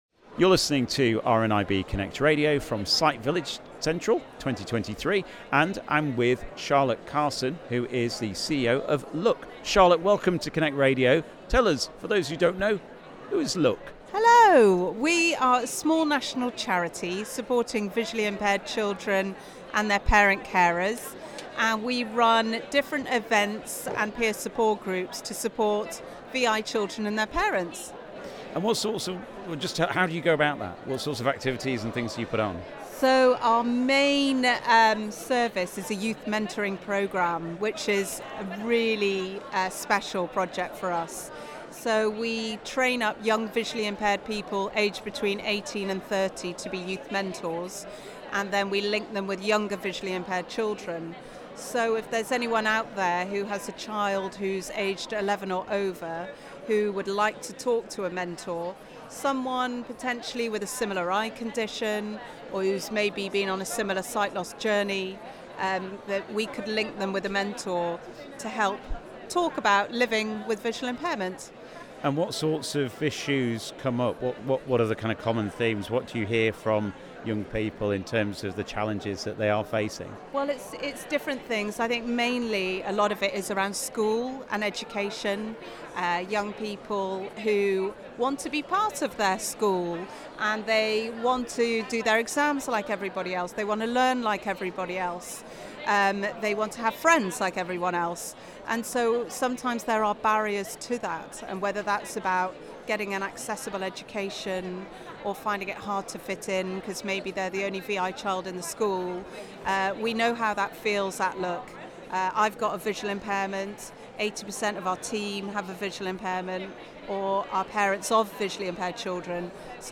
Sight Village Central 2023